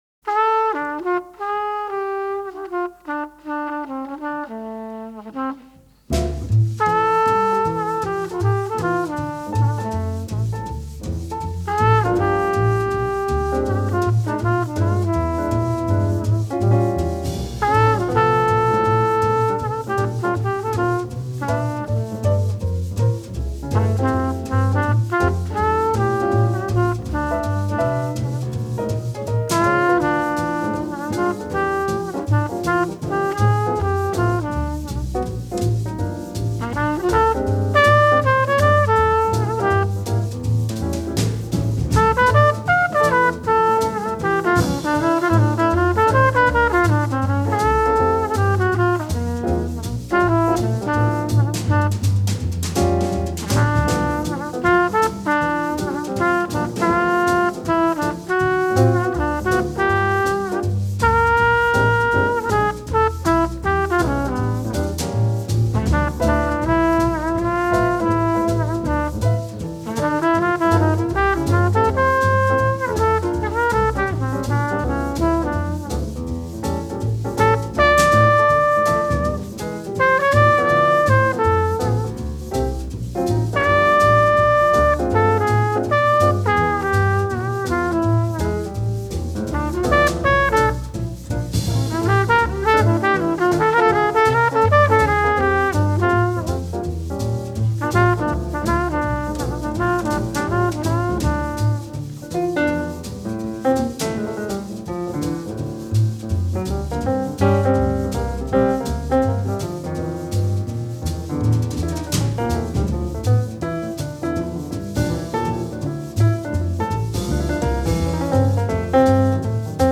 Jazz, Cool Jazz, Vocal Jazz